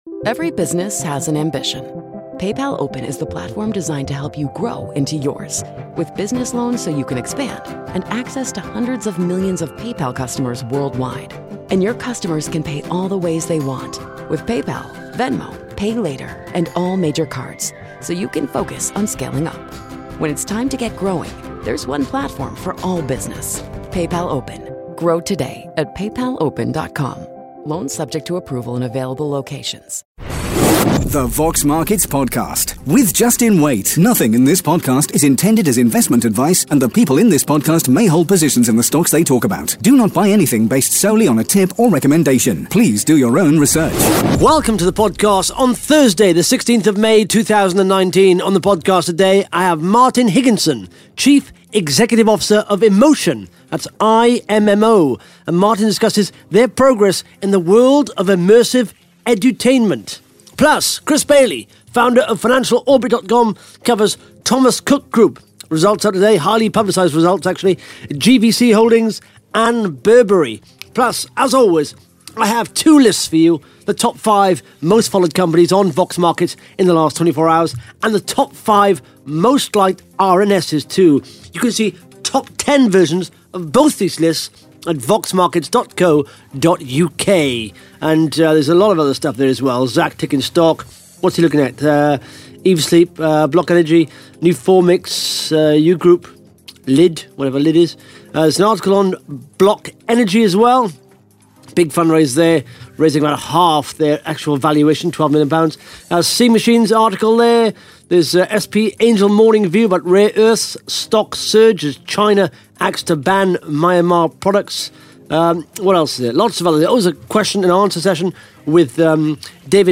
(Interview starts at 17 minutes 14 seconds) Plus the Top 5 Most Followed Companies & the Top 5 Most Liked RNS’s on Vox Markets in the last 24 hours.